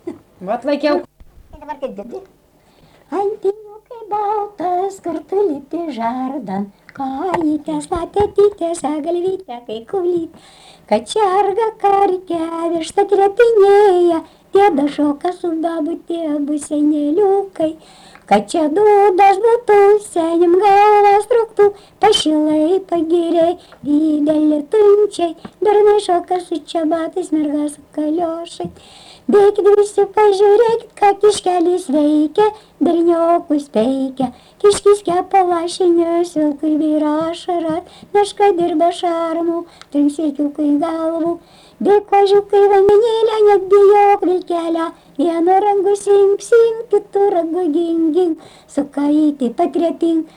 Dalykas, tema daina
Erdvinė aprėptis Bagdoniškis
Atlikimo pubūdis vokalinis